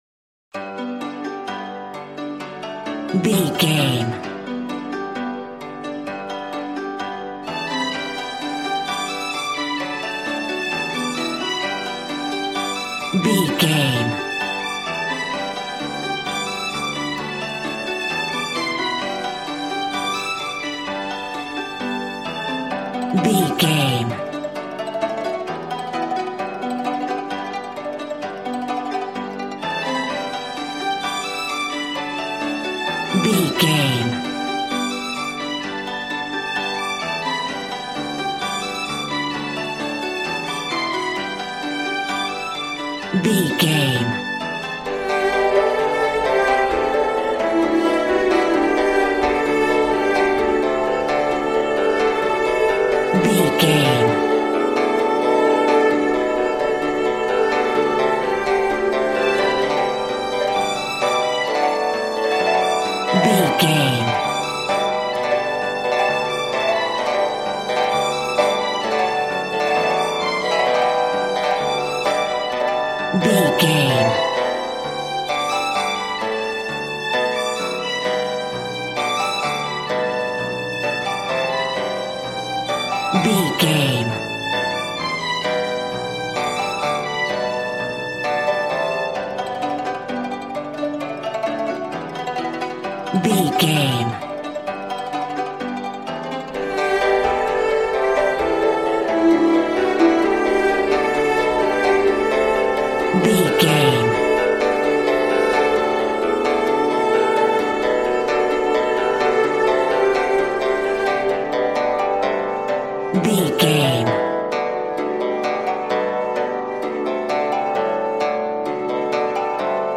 Ionian/Major
D
smooth
conga
drums